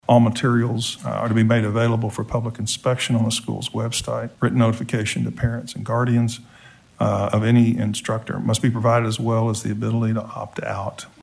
CLICK HERE to listen to commentary from Representative Mark Lepak.